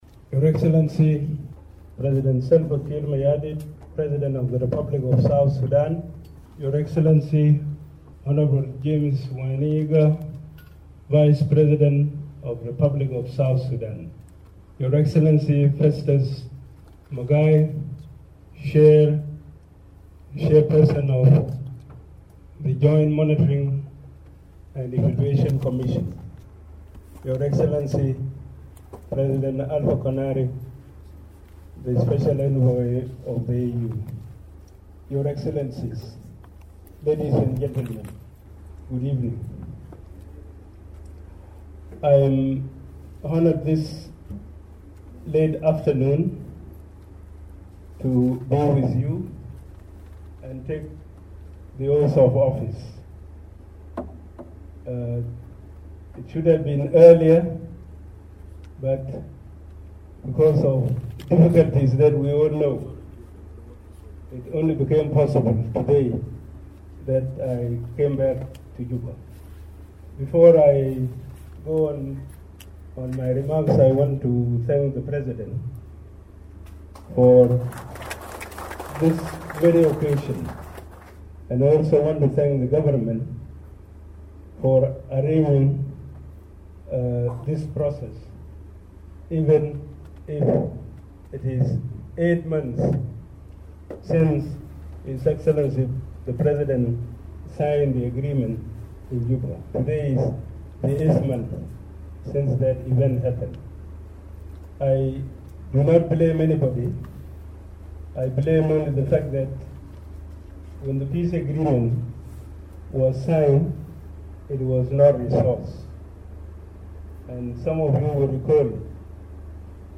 Machar made the commitment shortly after being sworn in at the President's office shortly after returning to Juba on Tuesday. We bring you Machar's remarks after taking his oath.